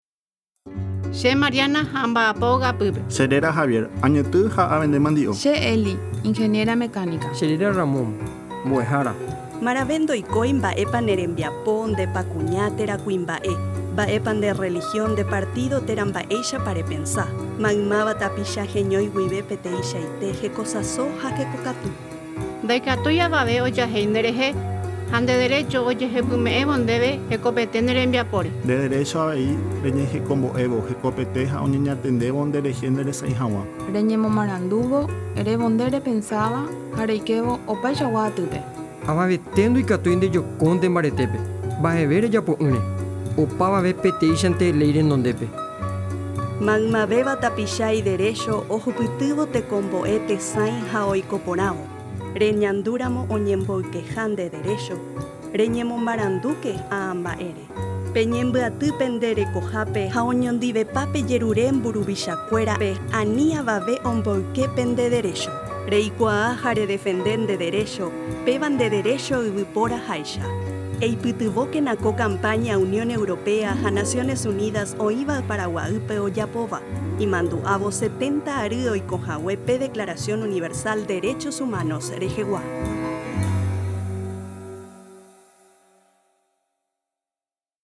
Spots Radiales